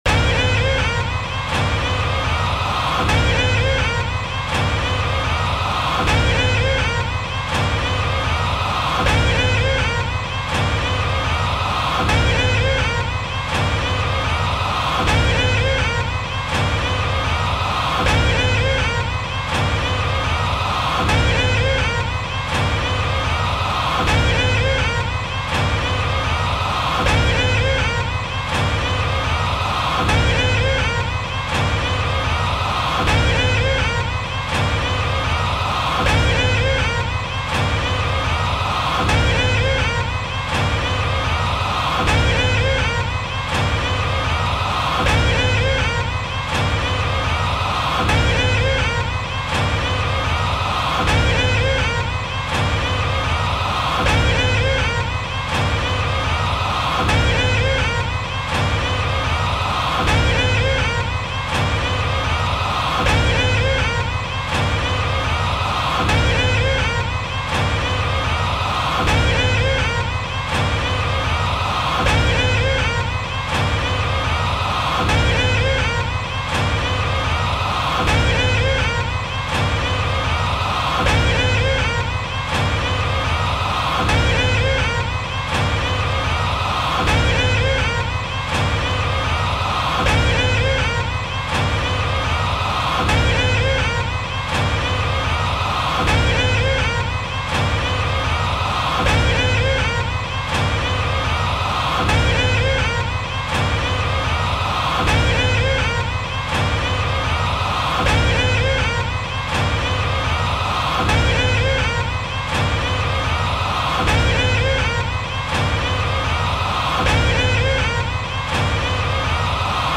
دانلود آهنگ پنی وایز طولانی از افکت صوتی انسان و موجودات زنده
جلوه های صوتی
دانلود صدای پنی وایز طولانی از ساعد نیوز با لینک مستقیم و کیفیت بالا